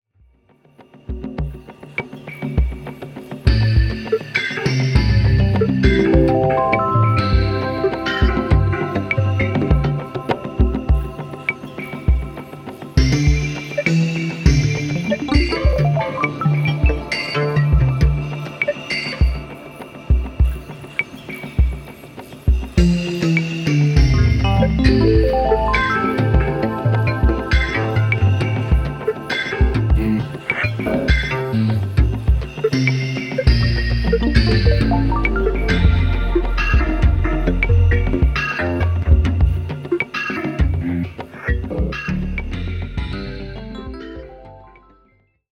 ディープなニューエイジ/アンビエント的アプローチの楽曲を展開